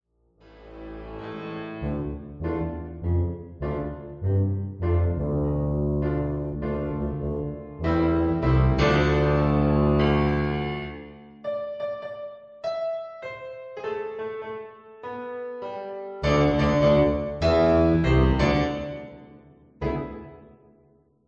Attractive original fun-based melodies